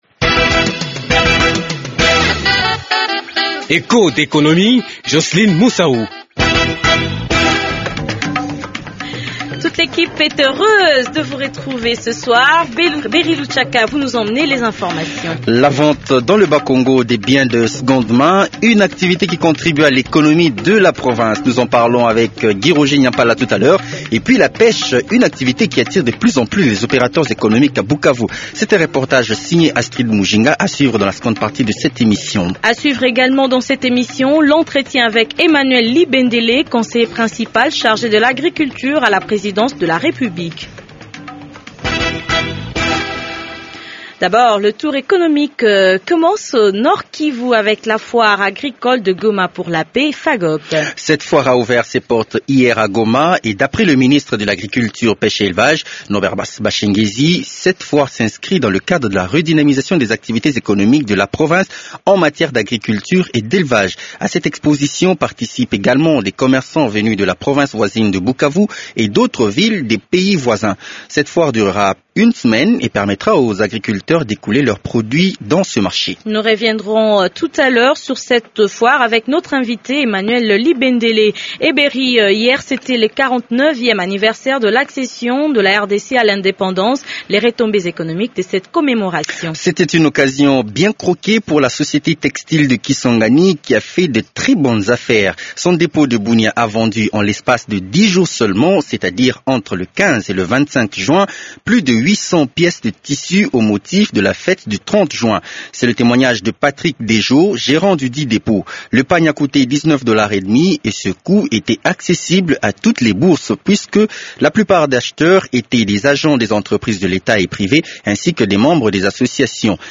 Le déroulement de cette foire avec l’invité de l’économie, Emmanuel Libendele, conseiller principal chargé de l’agriculture à la présidence de la république.